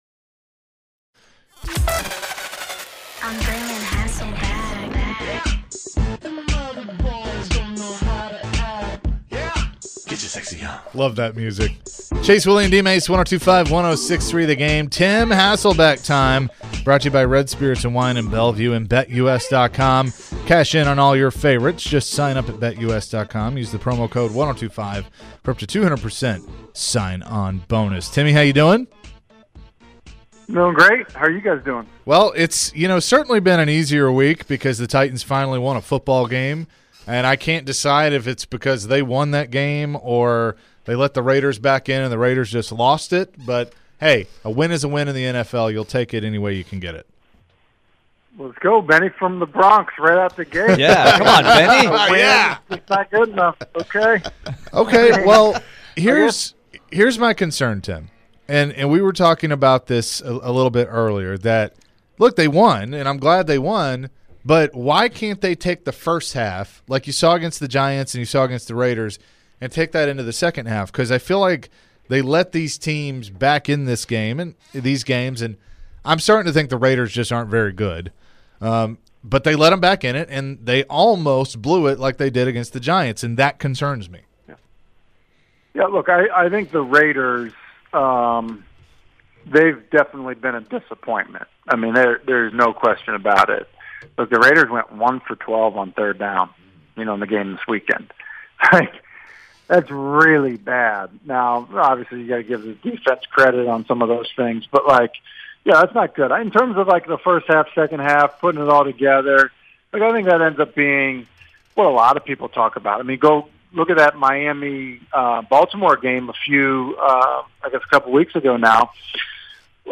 Tim Hasselbeck Full Interview (09-27-22)